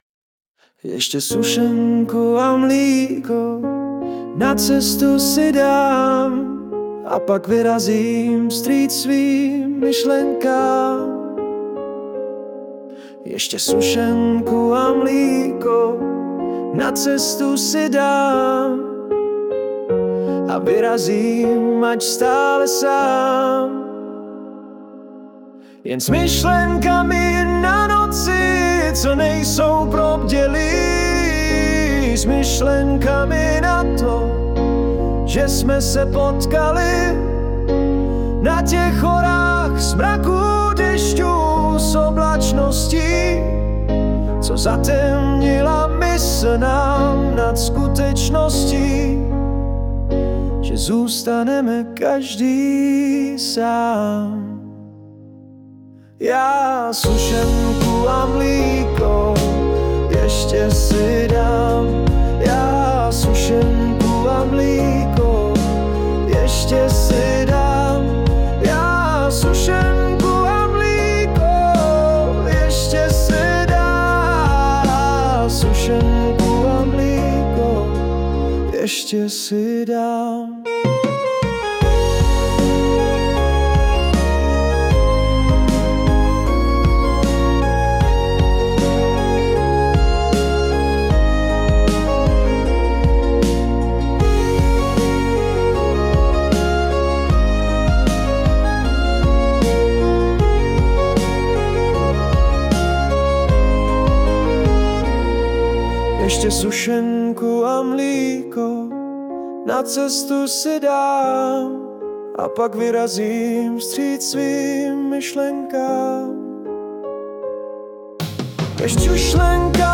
Manželka prohlásila, že by si dala nějakou sušenku a mlíko, tak jsem se toho chytil. Suno 4.5 pro, s jednou chybou ve výslovnosti v jednom slově, při opakování téže fráze ke konci už je výslovnost v pořádku, červenec 2025.